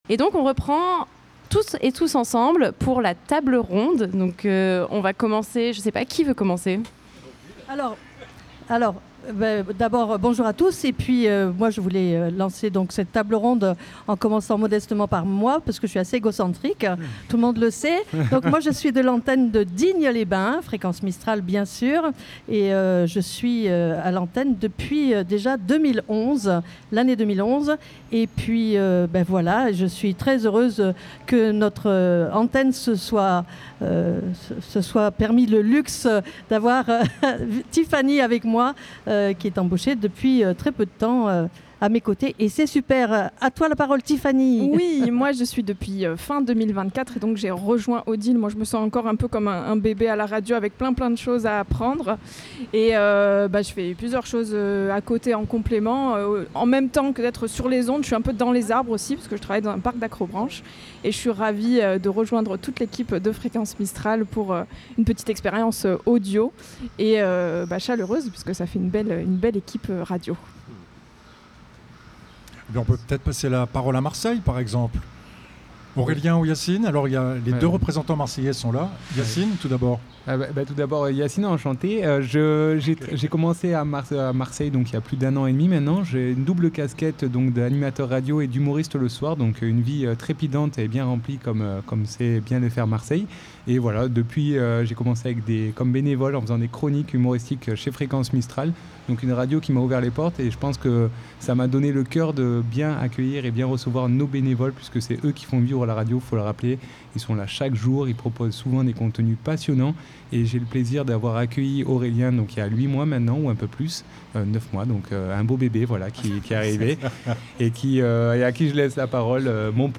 Fete de la Radio 2025 - La Table Ronde de l'équipe
Le 5 et 6 juin 2025 c'était la fête de la Radio dans toute la France. A cette occasion, toute l'équipe de Fréquence Mistral s'est retrouvée afin de vous proposer un plateau délocalisé en direct sur toute la journée sur Manosque.